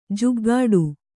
♪ juggāḍu